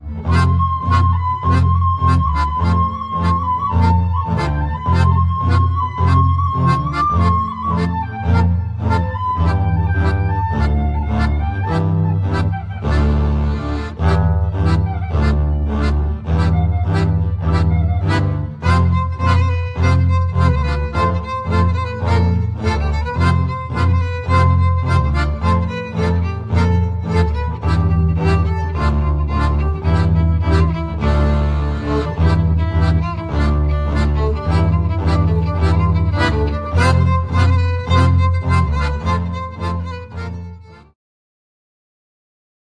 akordeon, ¶piew, tupan